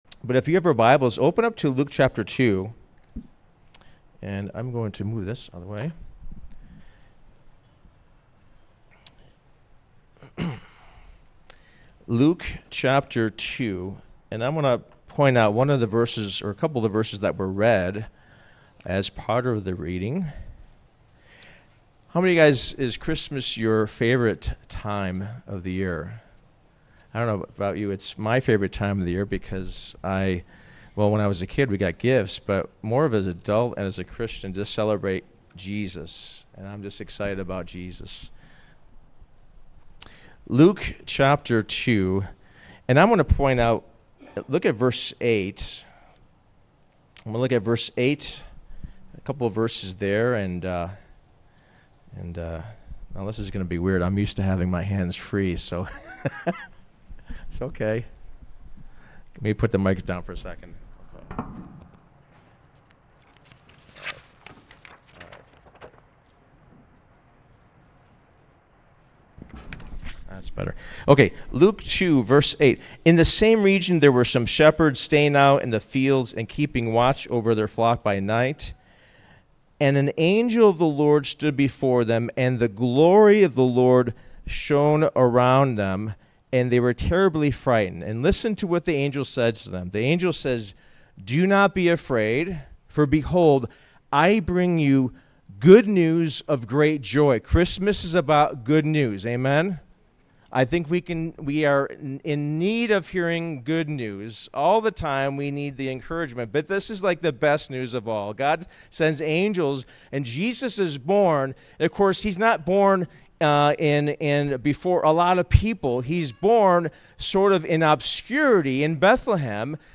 Sermon: Glory to God in The Highest